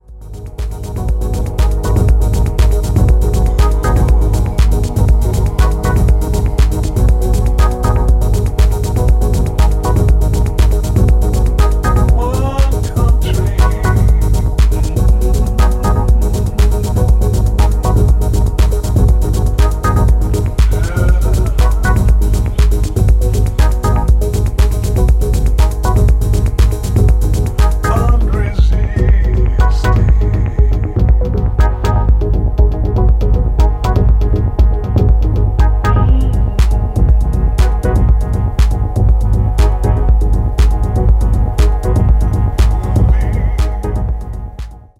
Very good deep US influenced house tracks.
House Detroit